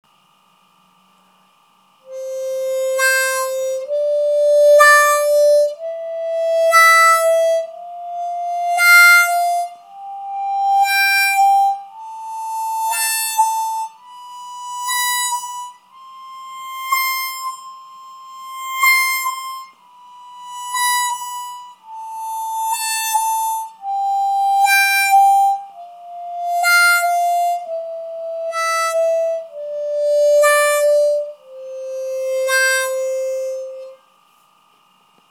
Сыграть гамму «до мажор» +4-4+5-5+6-6-7+7 и обратно +7-7-6+6-5+5-4+4, на каждой ноте делая по одному эффекту вау-вау.
Vau-Vau.mp3